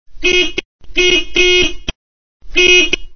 Descarga de Sonidos mp3 Gratis: bocina 3.
horn3.mp3